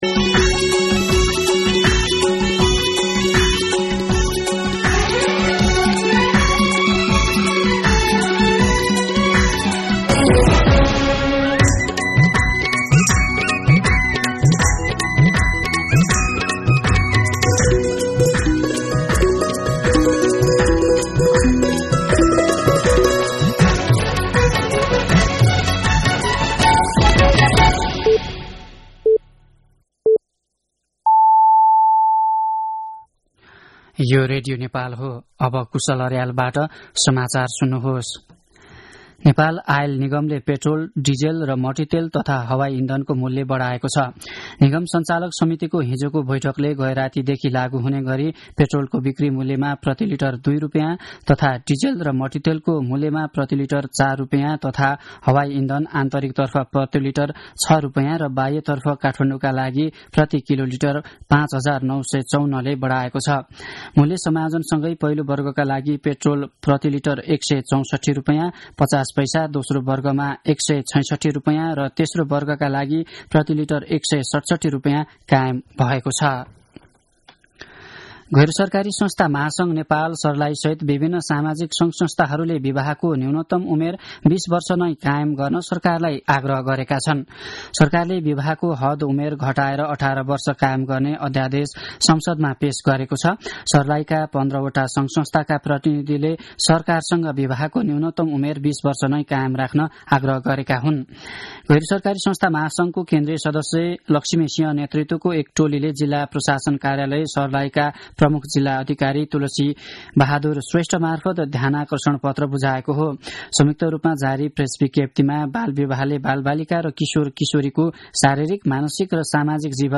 मध्यान्ह १२ बजेको नेपाली समाचार : २० माघ , २०८१
12-pm-Nepali-News.mp3